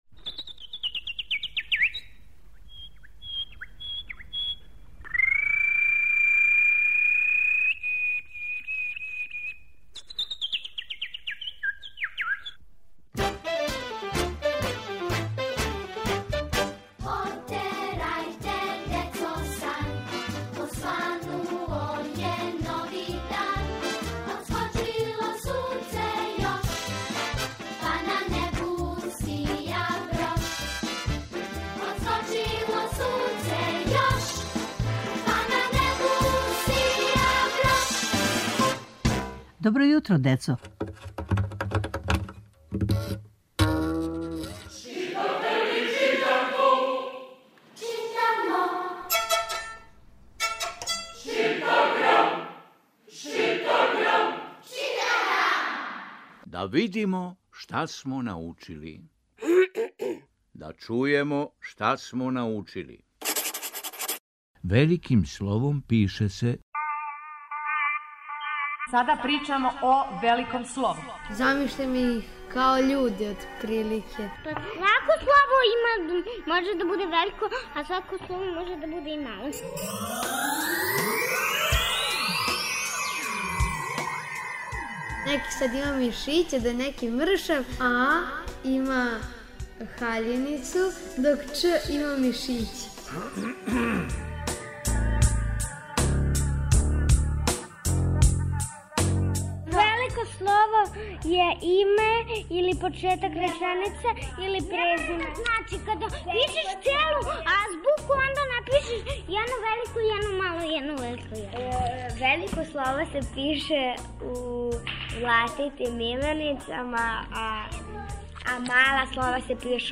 Читанка за слушање. Данас се подсећамо шта смо научили о великом слову.